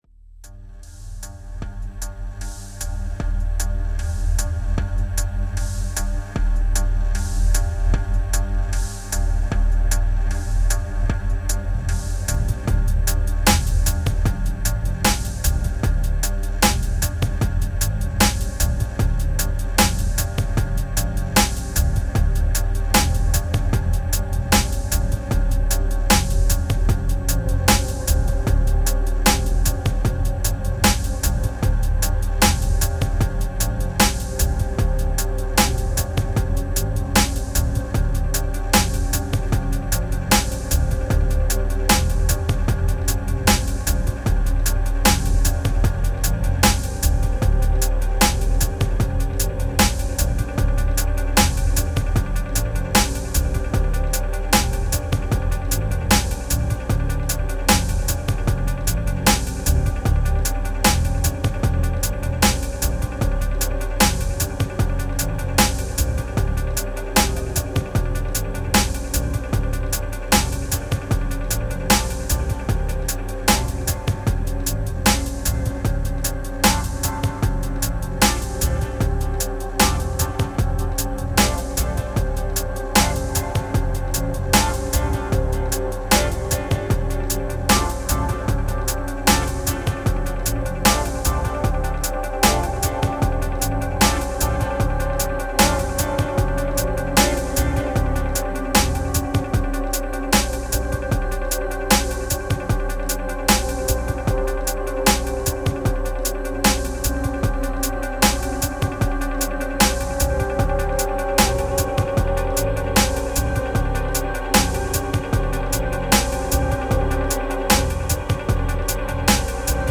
2200📈 - 94%🤔 - 76BPM🔊 - 2023-09-11📅 - 1313🌟
Phat morning beat. One take.